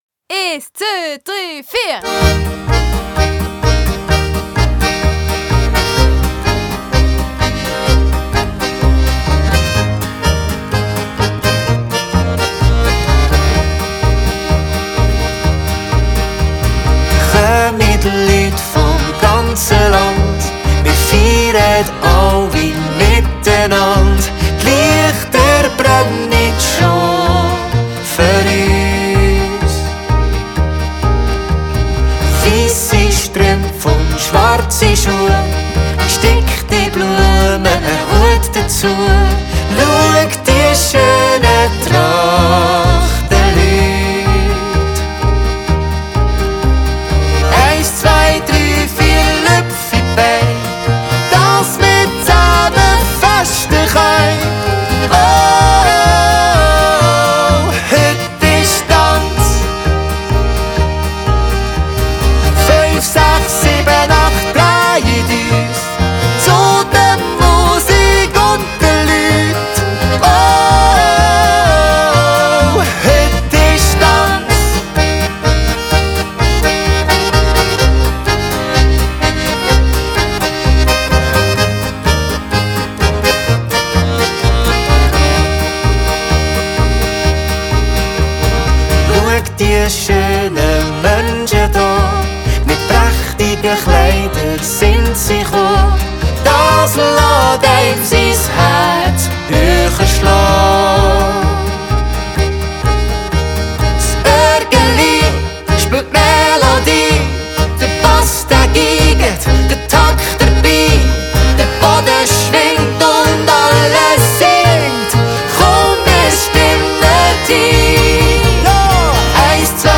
(mit Einzähler)